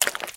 STEPS Swamp, Walk 09.wav